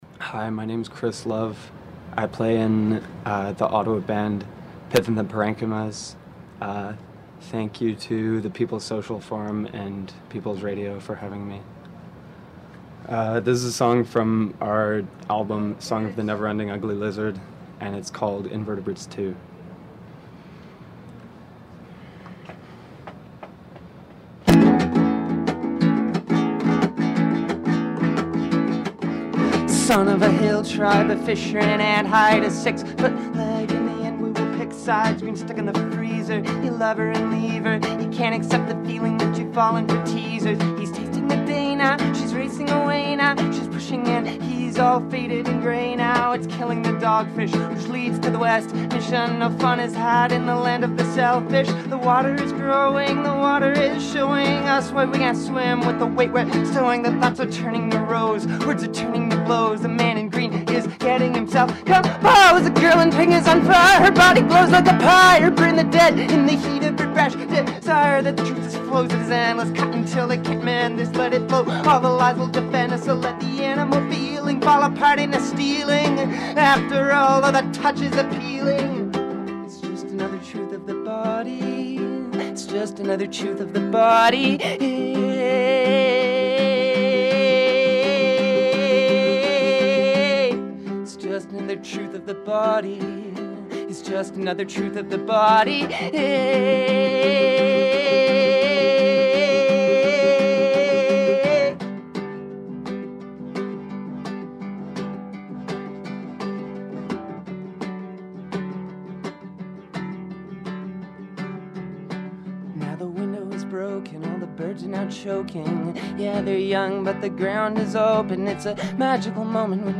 Acoustic performance of songs from